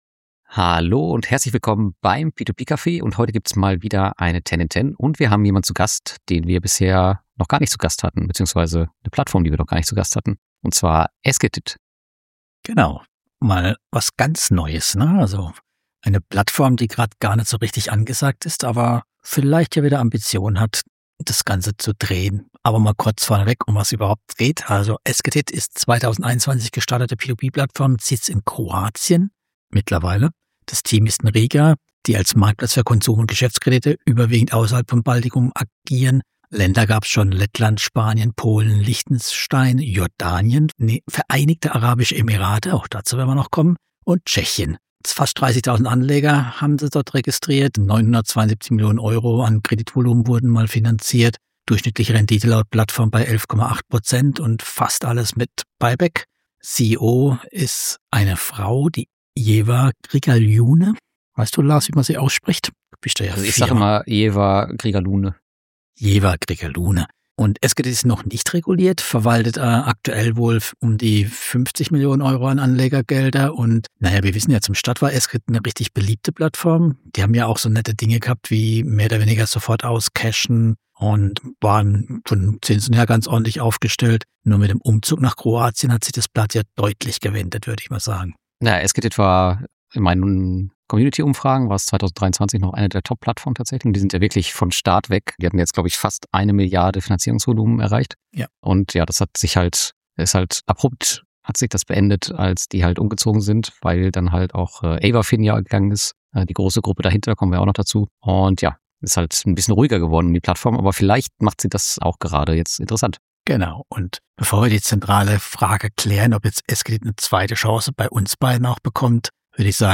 stellt sich im Interview zum ersten Mal überhaupt der Öffentlichkeit.